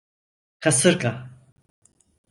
Pronunciado como (IPA)
/ka.ˈsɯɾ.ɡa/